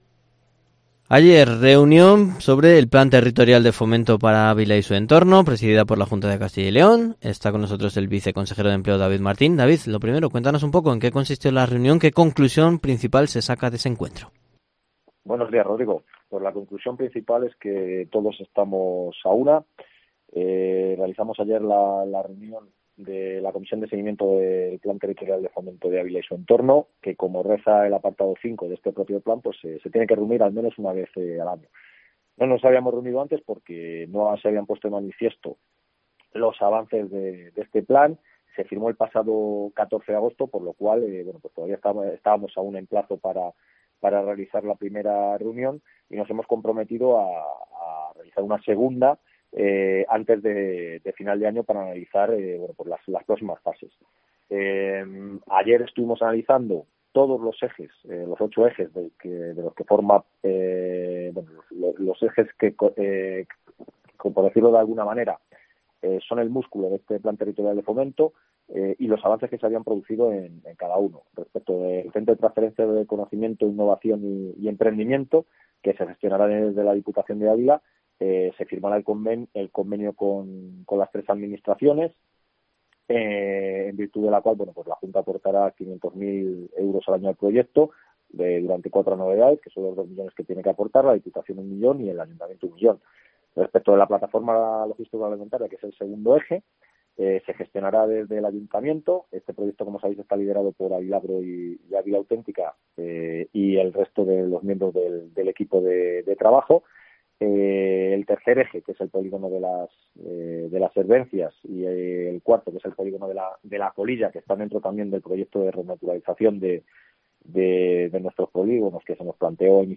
Entrevista completa con el viceconsejero de empleo, David Martín en COPE